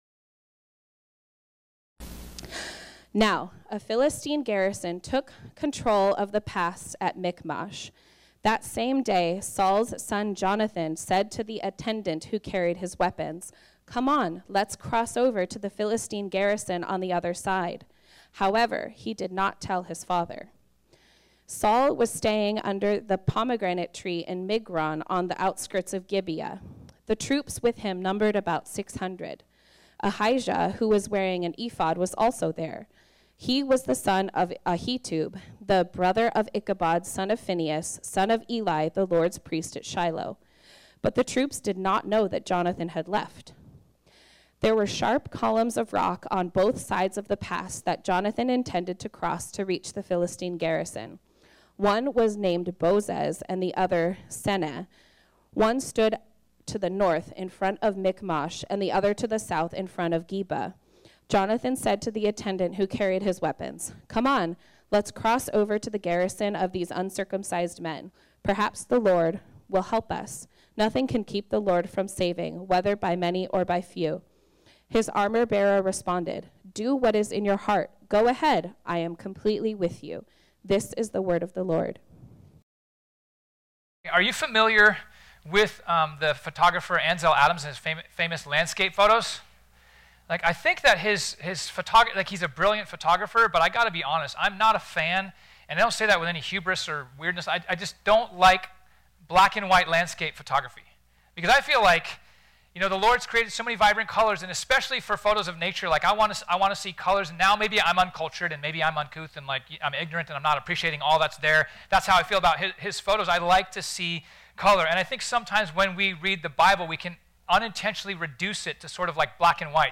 This sermon was originally preached on Sunday, May 14, 2023.